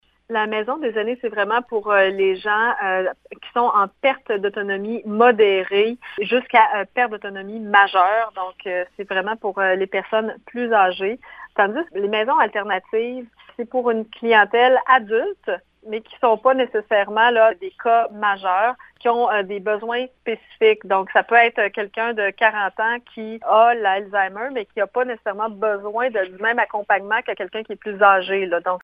La ministre explique la différence entre les deux types d’hébergement qui seront offerts :